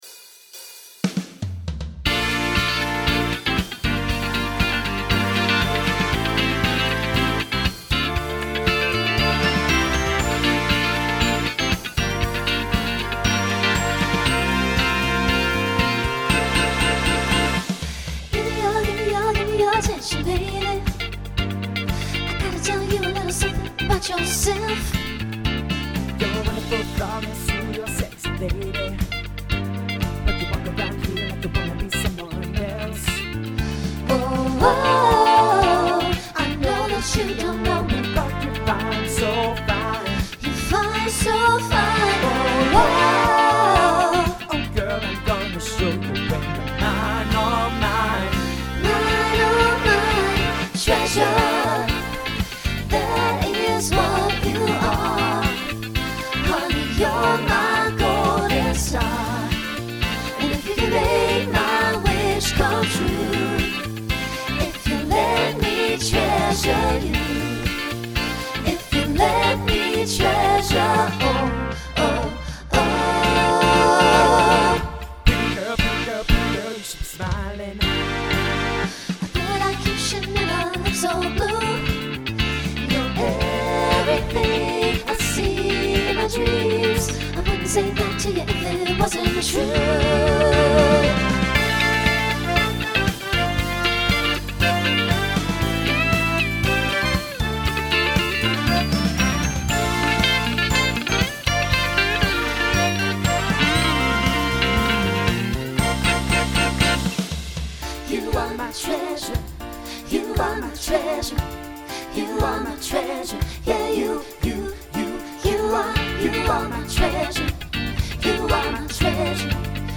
New SSA voicing for 2022.
Pop/Dance
Mid-tempo